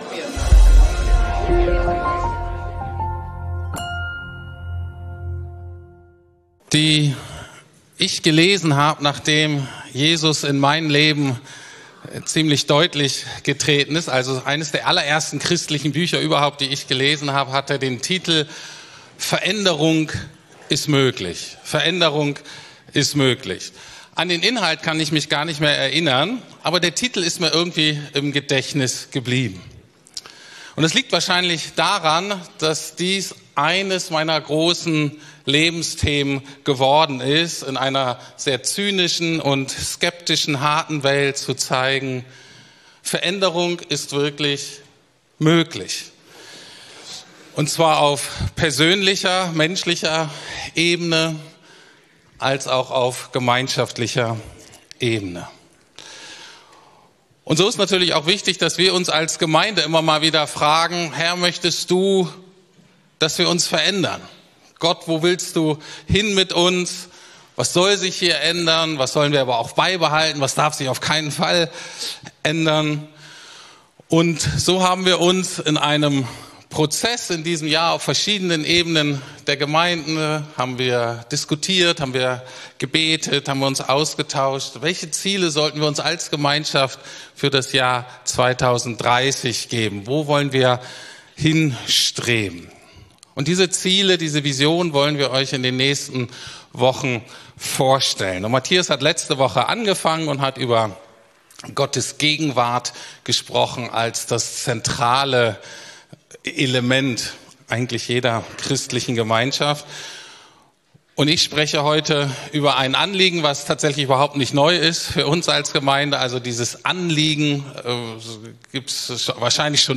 Wir wachsen im Glauben ~ Predigten der LUKAS GEMEINDE Podcast